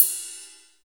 LITERIDEEDG.wav